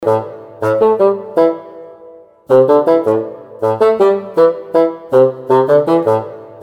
красивые
спокойные
без слов
инструментальные